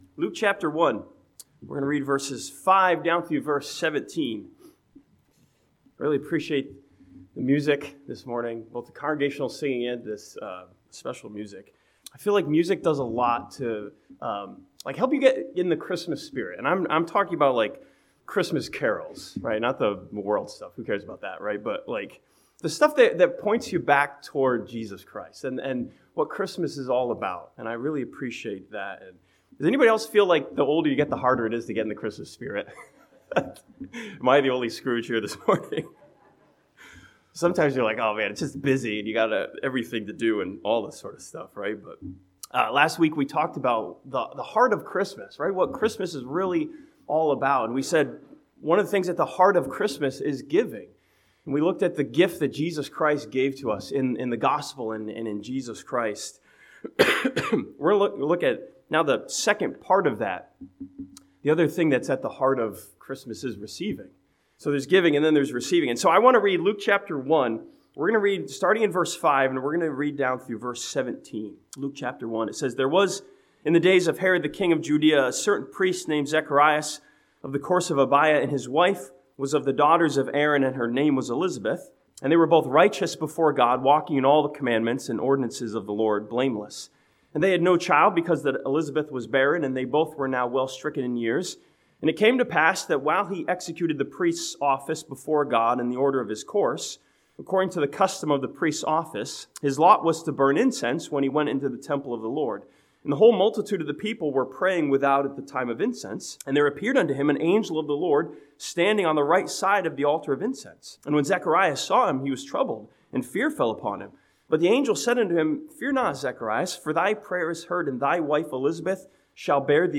This sermon from Luke chapter 1 looks at how the act of receiving is at the heart of Christmas and the Christmas story.